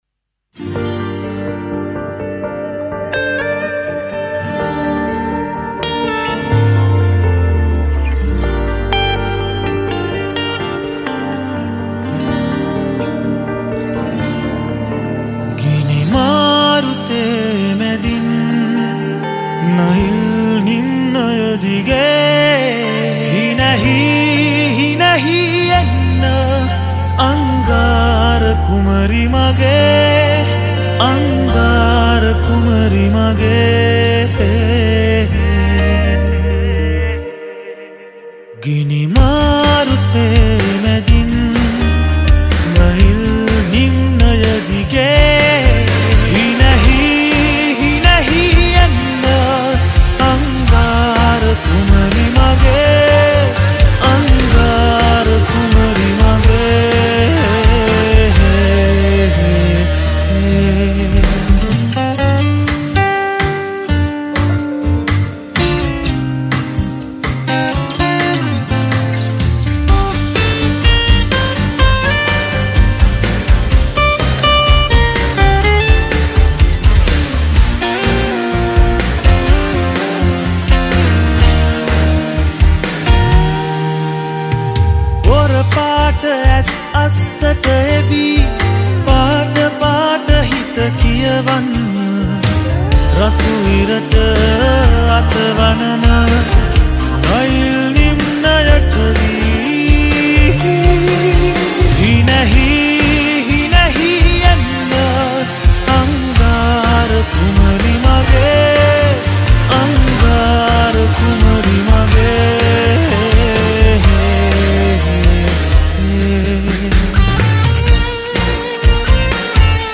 Guitars